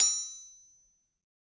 Anvil_Hit1_v2_Sum.wav